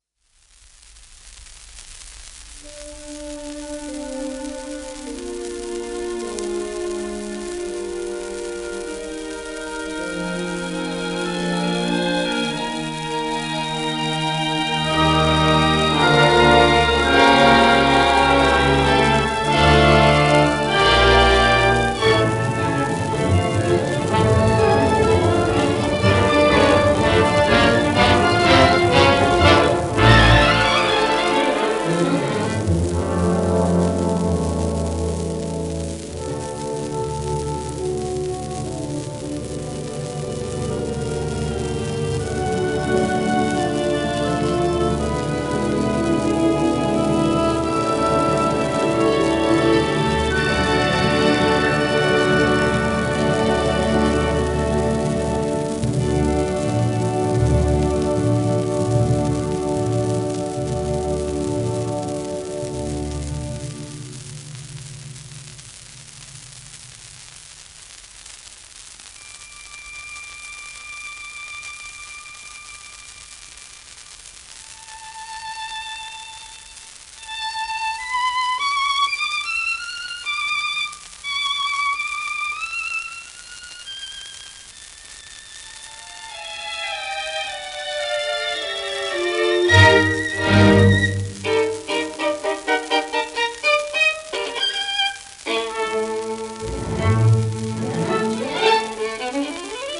盤質A- *斑点状の薄い痕(音への影響は感じない)、薄いスレ
1935年頃録音